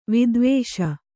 ♪ vidvēṣa